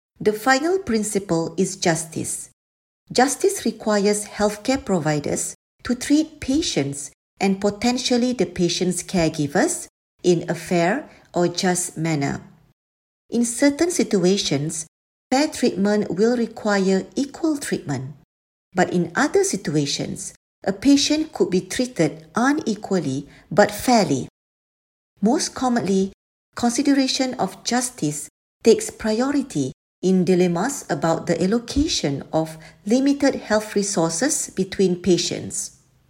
Narration audio (MP3) Contents Home What is Medical Ethics?